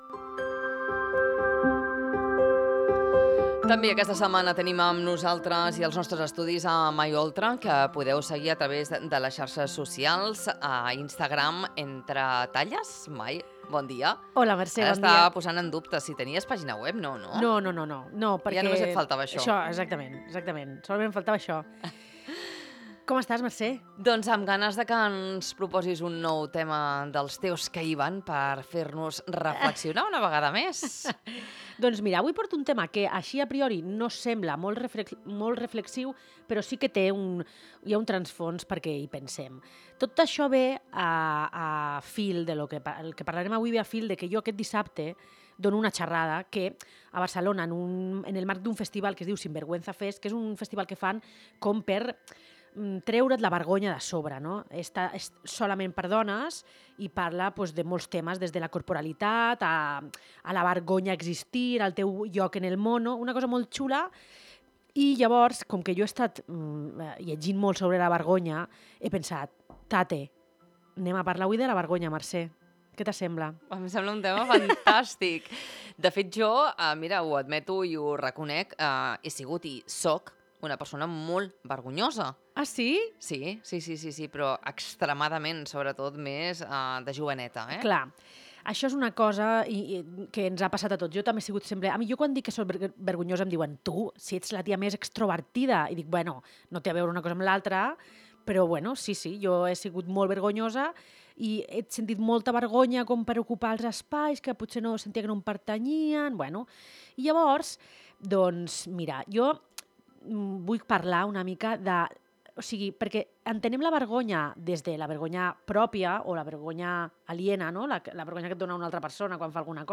I com podem aprendre a entendre-la i superar-la per no deixar que ens limiti?Una conversa valenta i necessària sobre autoestima, por i llibertat personal.🎧 Escolta-la